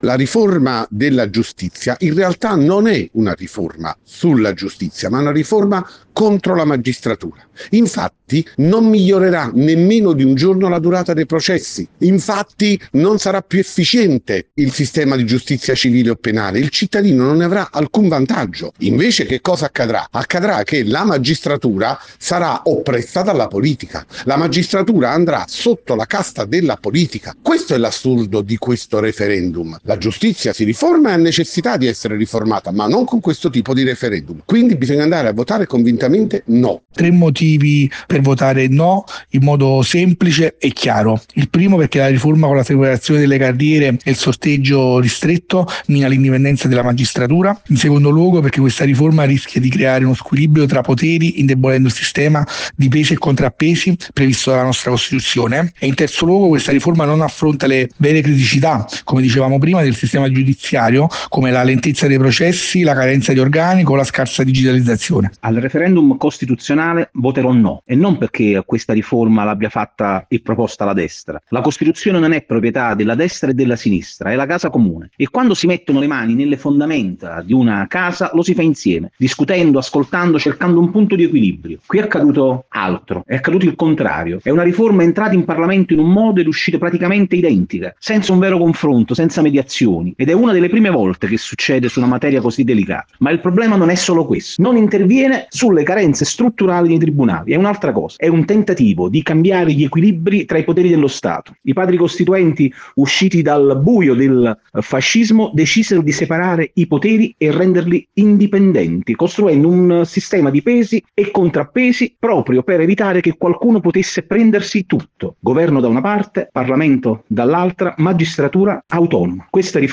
Per le ragioni del No ai nostri microfoni il Deputato e Vicepresidente della Camera dei Deputati Sergio Costa